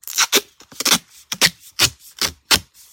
tape_pull2.wav